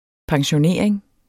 Udtale [ pɑŋɕoˈneˀɐ̯eŋ ]